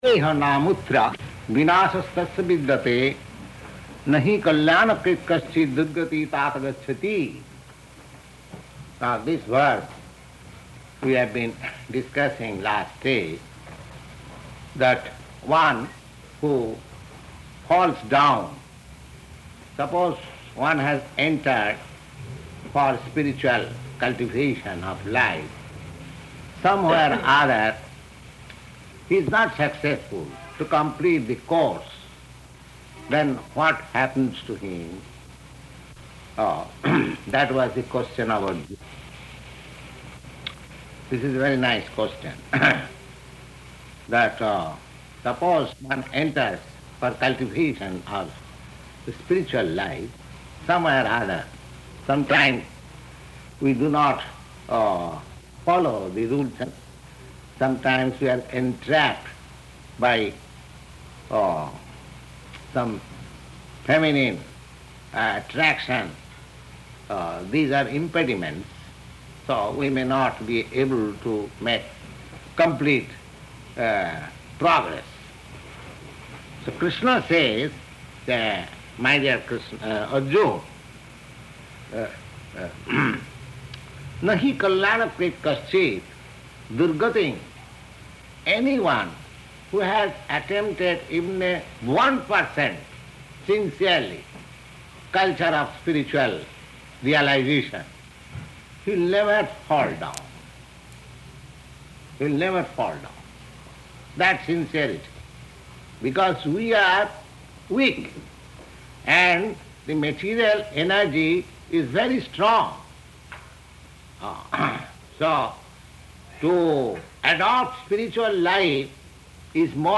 Location: New York